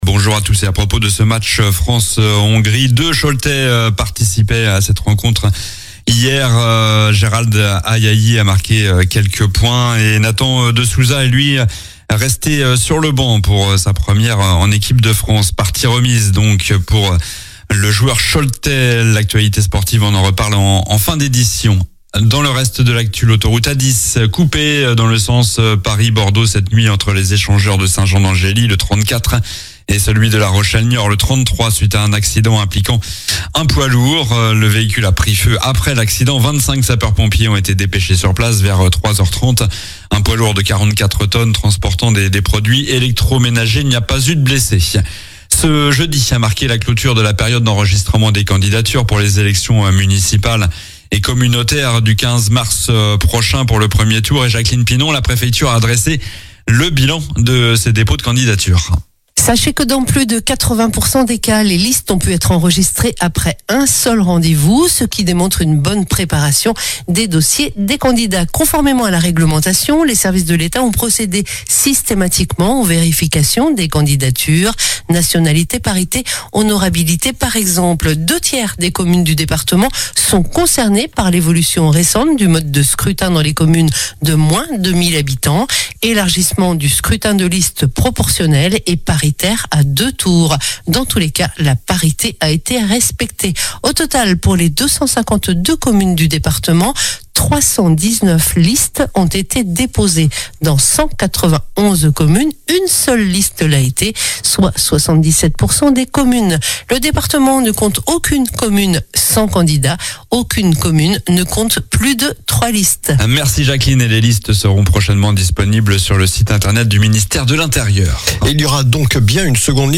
Journal du samedi 28 février (matin)